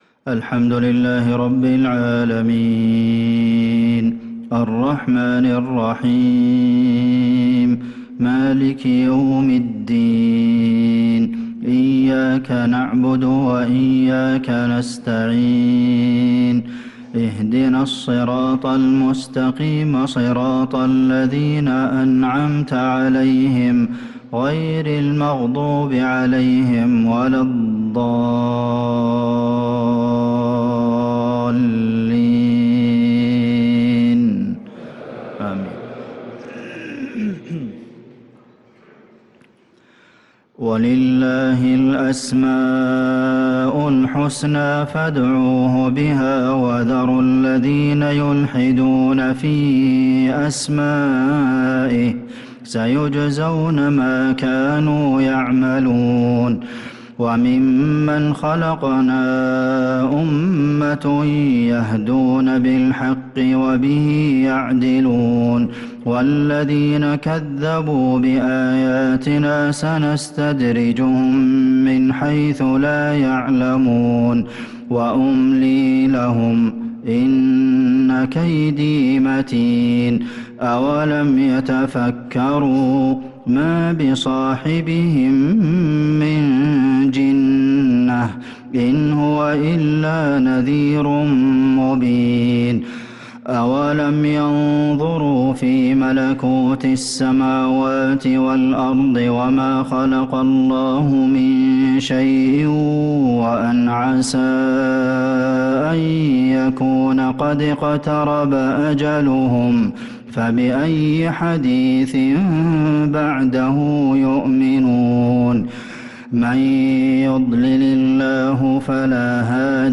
صلاة العشاء للقارئ عبدالمحسن القاسم 18 رجب 1445 هـ
تِلَاوَات الْحَرَمَيْن .